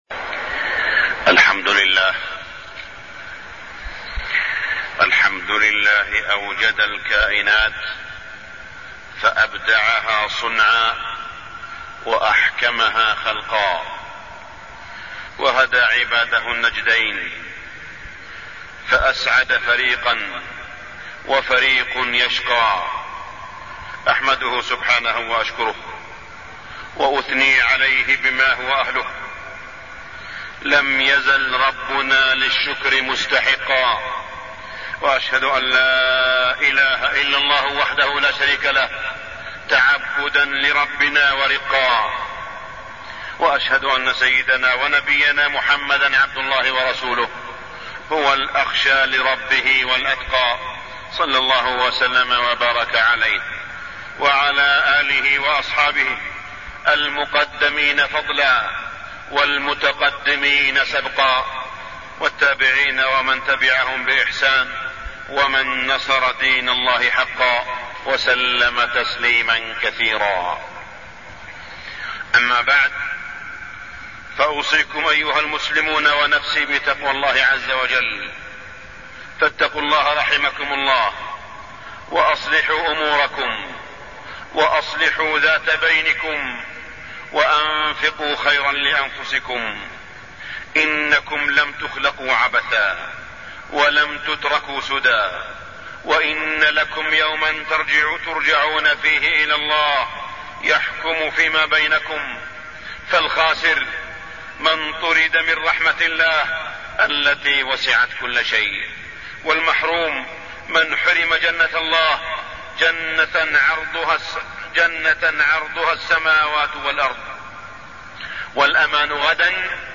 تاريخ النشر ٢٧ رجب ١٤٢٠ هـ المكان: المسجد الحرام الشيخ: معالي الشيخ أ.د. صالح بن عبدالله بن حميد معالي الشيخ أ.د. صالح بن عبدالله بن حميد معجزة الإسراء والعراج